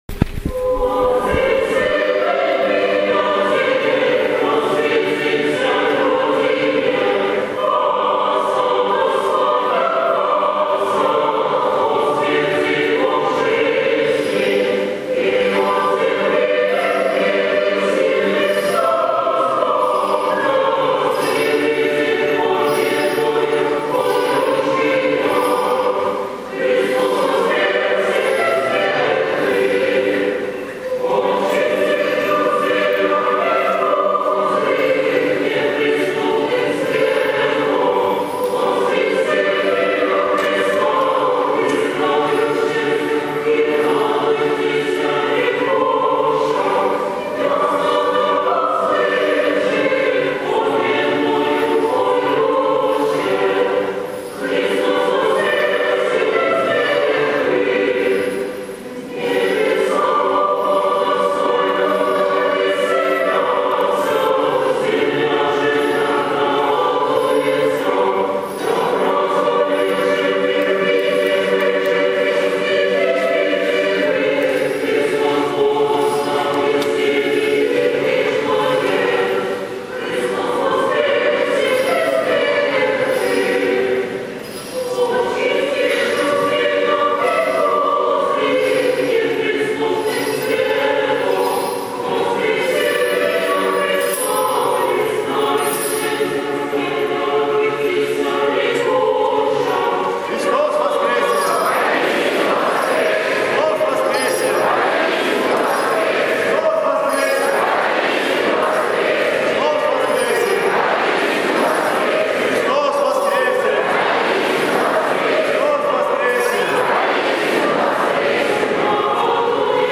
Пасхальное богослужение в Свято-Троицком кафедральном соборе